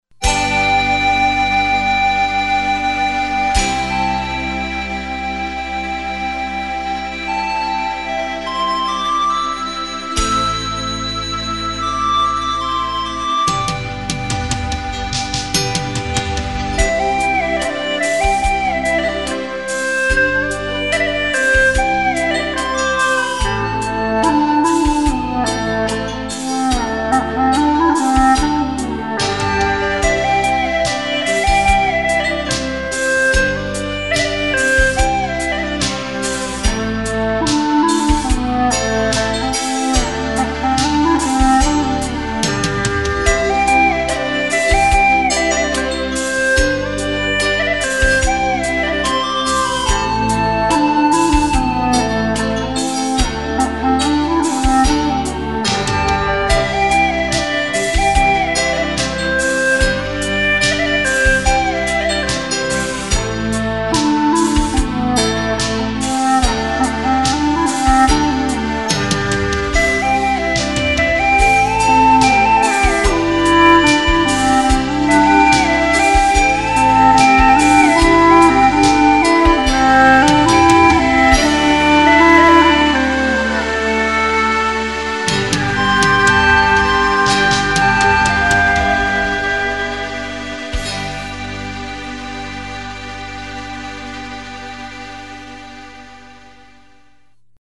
调式 : C